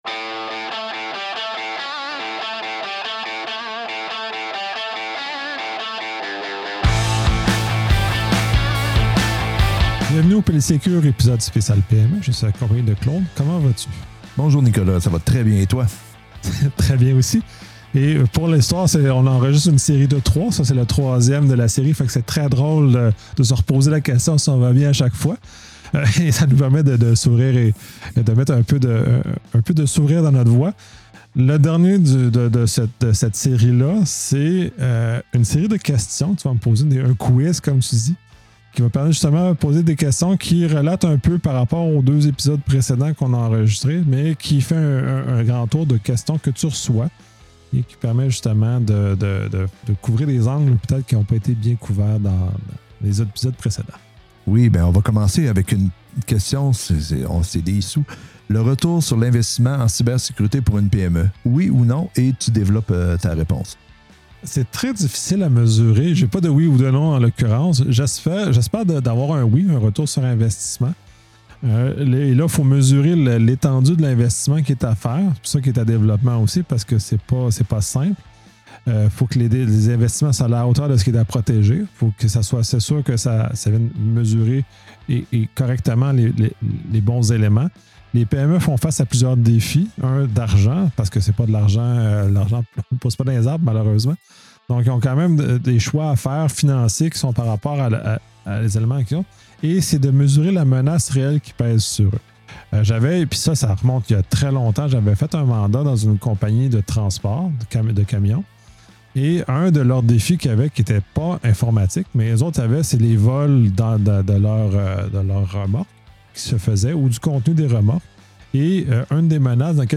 quiz interactif